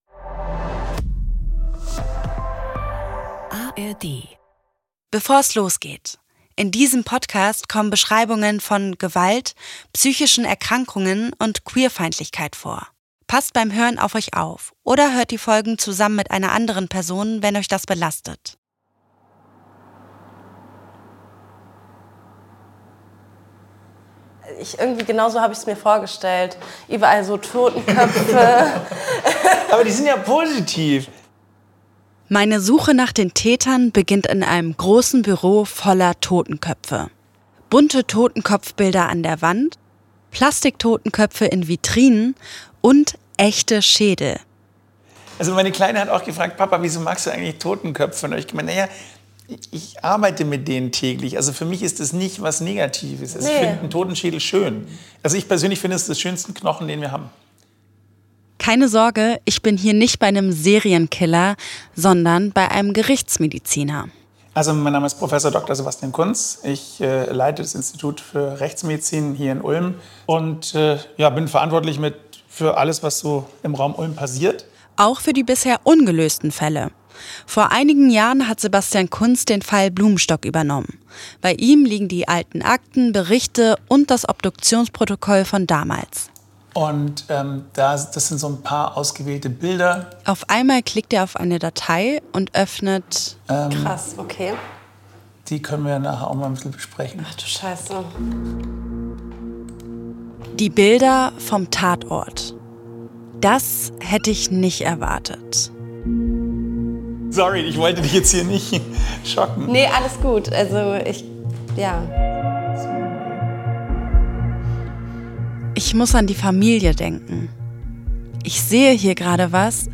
Eine Produktion des SWR